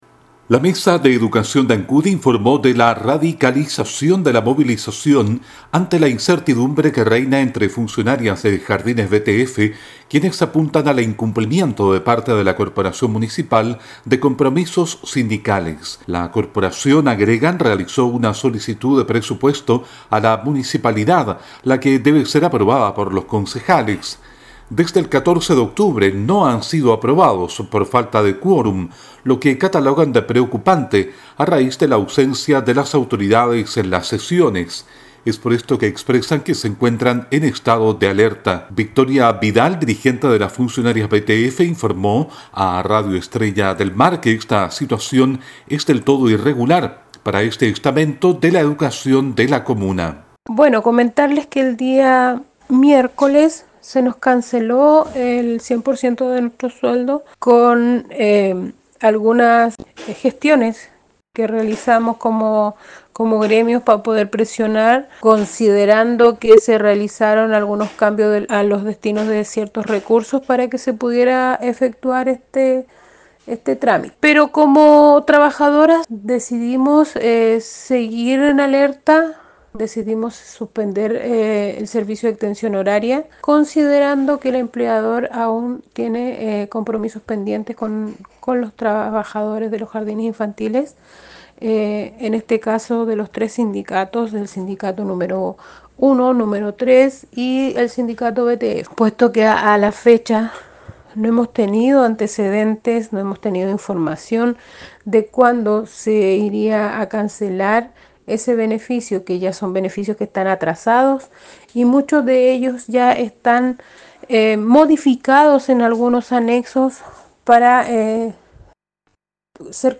Todo en el siguiente despacho